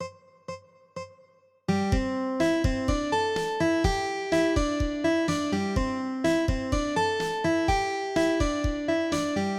のイントロ・リフ